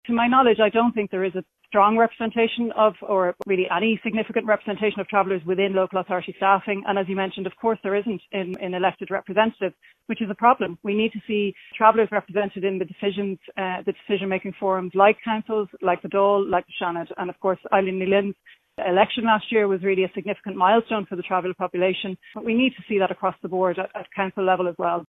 Chief Commissioner of the IHREC Sinead Gibney says there needs to be more representation within local authorities…………